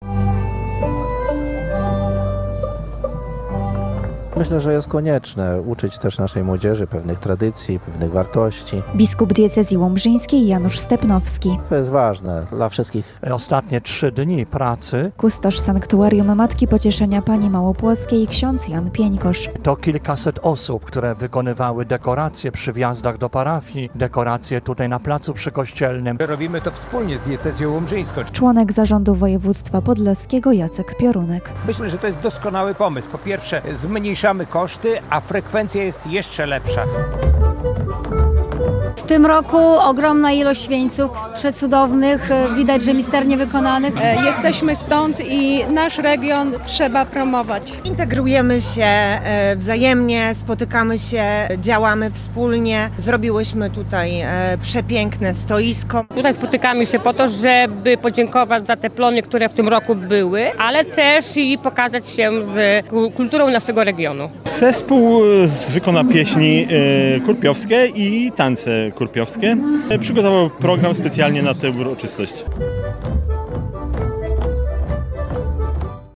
Przy dźwiękach muzyki ludowej, tańca i kulinarnych przysmakach – tak świętowali mieszkańcy regionu Dożynki Wojewódzko-Diecezjalne w Małym Płocku.